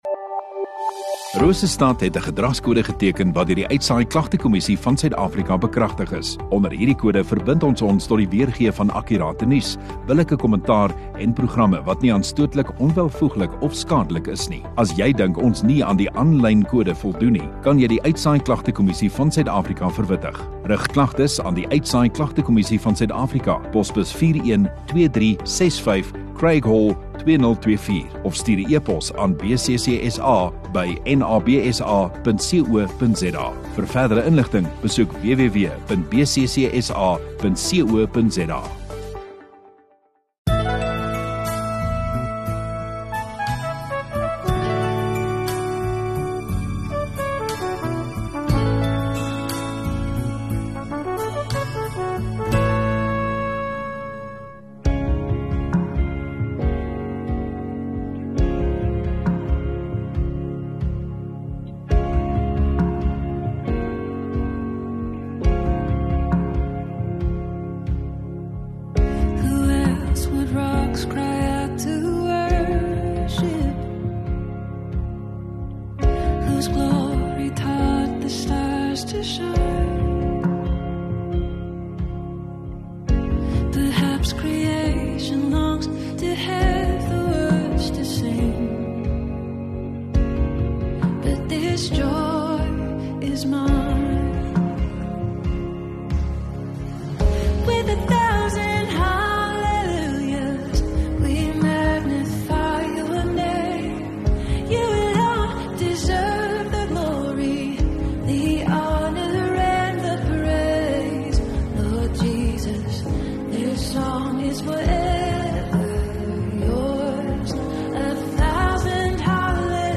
1 Mar Saterdag Oggenddiens